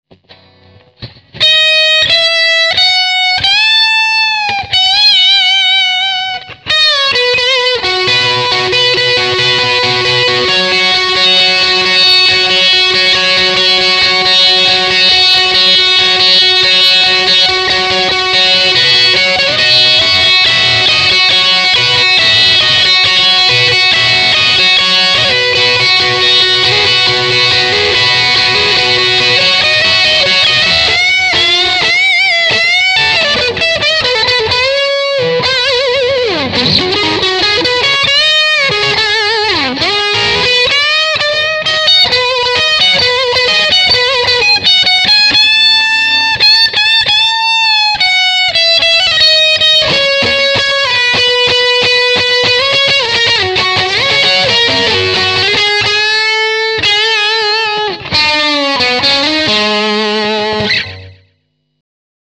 (Lead)
This soundbyte was done using a BOSS BR1180
digital recorder..